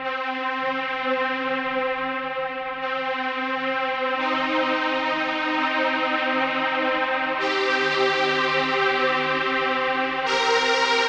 Orc Strings 02.wav